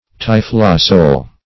Search Result for " typhlosole" : The Collaborative International Dictionary of English v.0.48: Typhlosole \Typh"lo*sole\, n. [Gr.